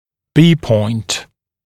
[biː pɔɪnt][биː пойнт]точка B (цефалометрическая точка)